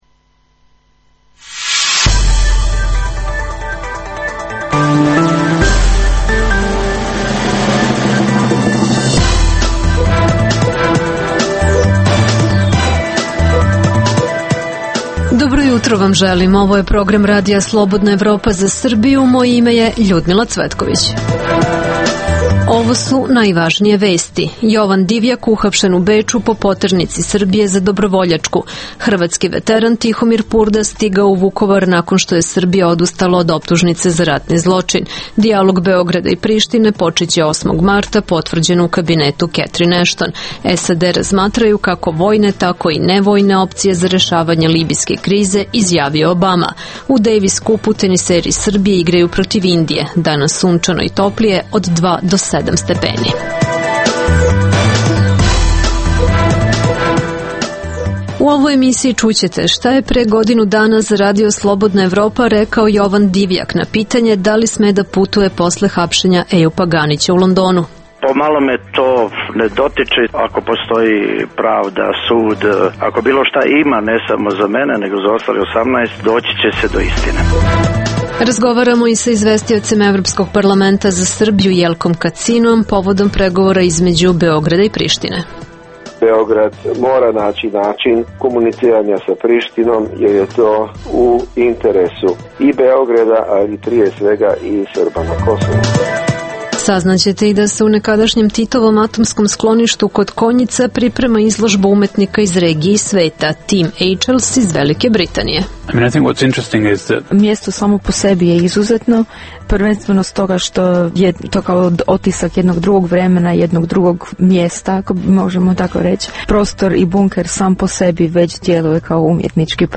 U ovoj emisiji možete čuti: - Jovan Divjak uhapšen u Beču po poternici Srbije za Dobrovoljačku. Čućete prve reakcije iz Sarajeva i razgovor za RSE sa Divjakom od pre godinu dana kada je u Lonodnu uhapšen Ejup Ganić.
Za RSE govori izvestioc Evropskog parlamenta za Srbiju Jelko Kacin.